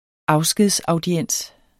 Udtale [ ˈɑwsgeðs- ]